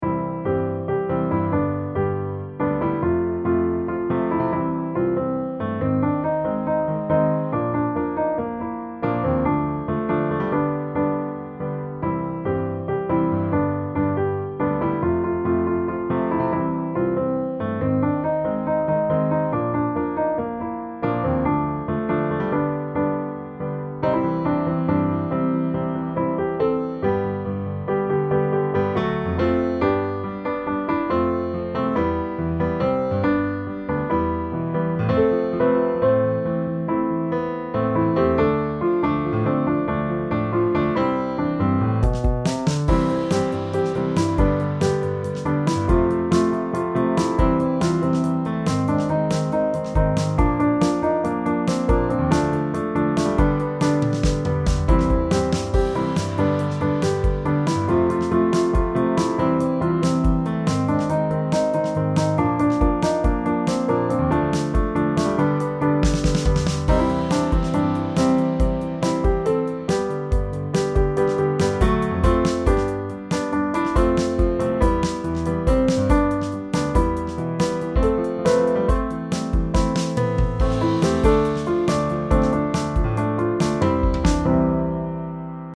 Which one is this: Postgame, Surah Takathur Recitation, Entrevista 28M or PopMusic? PopMusic